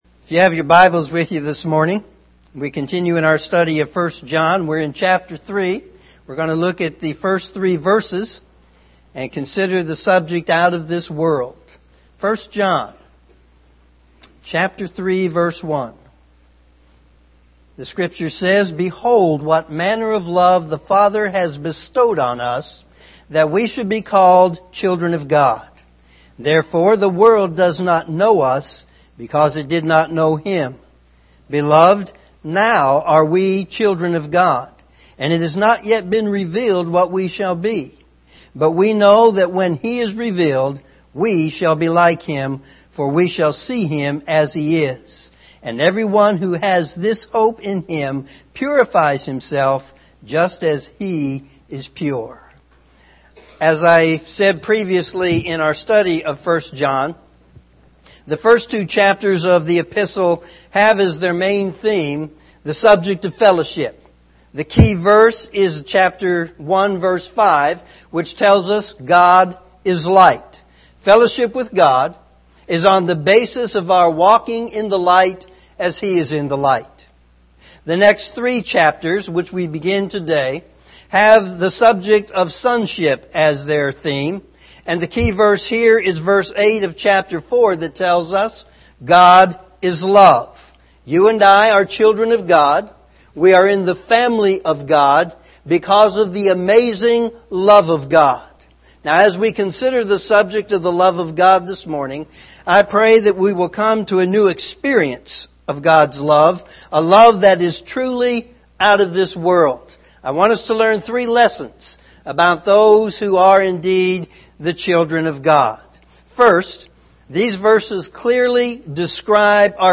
Morning Sermon
sermon3-11-18am.mp3